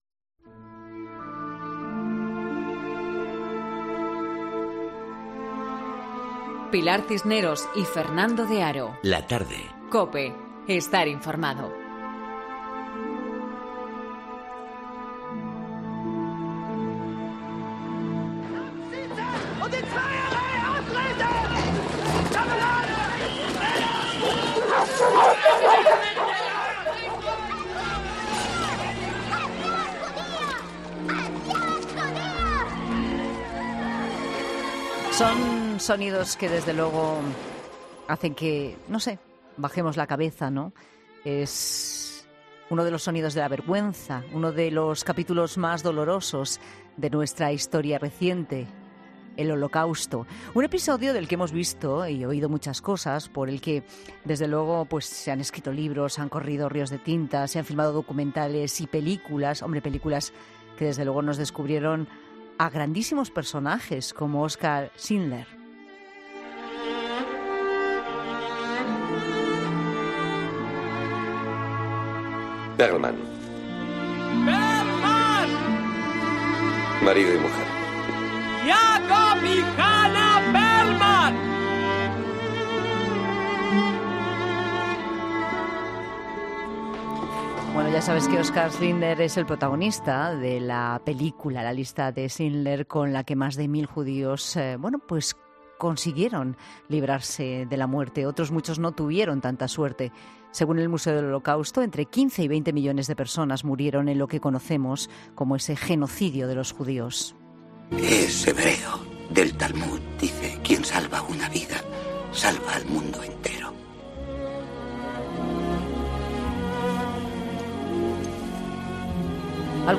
cuentan su experiencia en el seminario de educadores de España, celebrado en esta ocasión en Jerusalén.